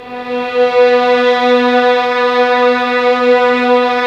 Index of /90_sSampleCDs/Roland - String Master Series/STR_Vlns 6 p-mf/STR_Vls6 mf amb